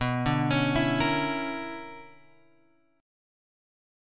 BmM11 chord {7 5 8 6 5 x} chord